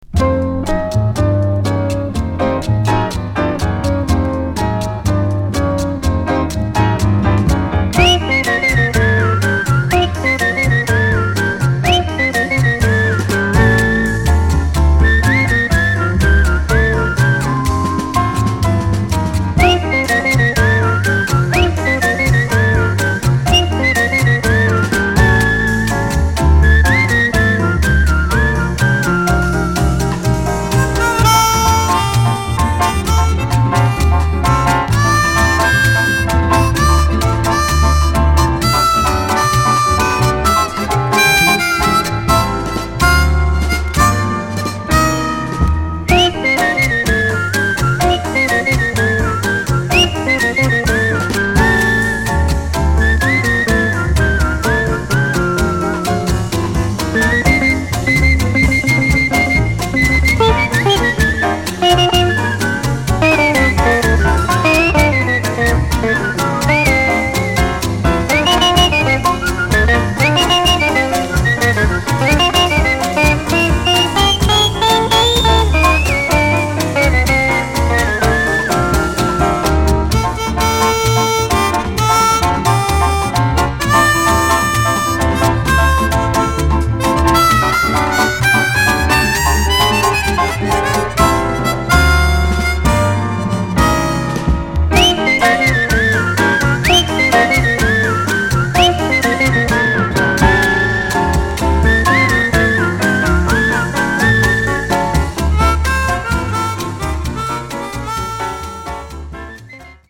1969年にスウェーデンで録音された